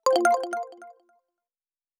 Special & Powerup (33).wav